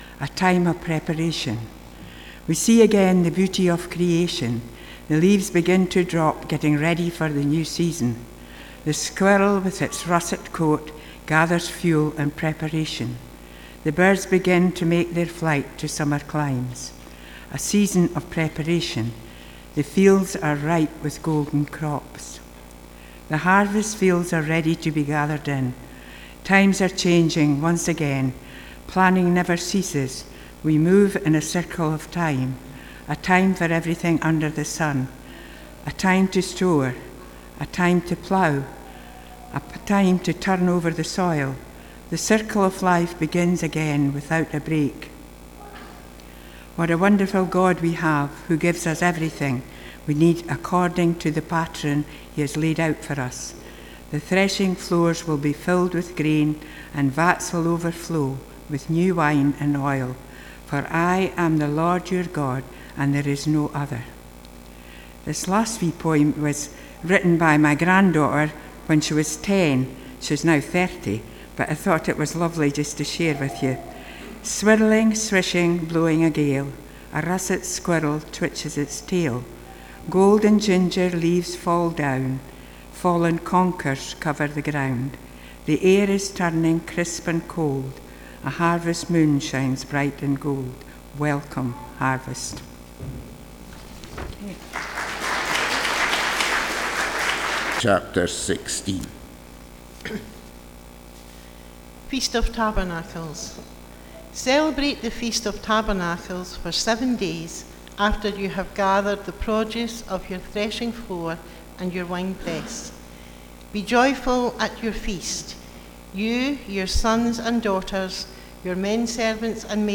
Passage: Deuteronomy 16:13-17, Ruth 2:1-7, John 7:37-38 Service Type: Sunday Morning